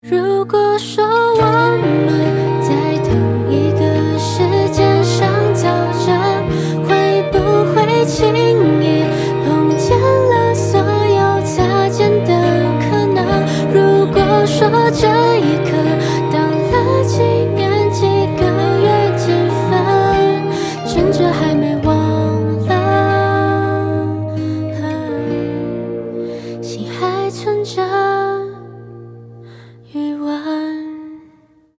Pop folk, Chinese pop